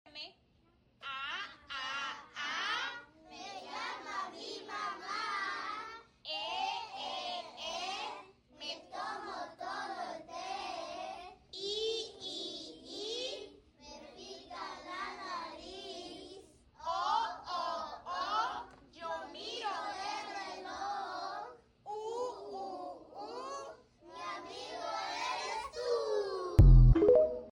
Sin vocales no leemos mis amores... les comparto esta canción fácil y rápida que a tus estudiantes estoy segura les va a encantar...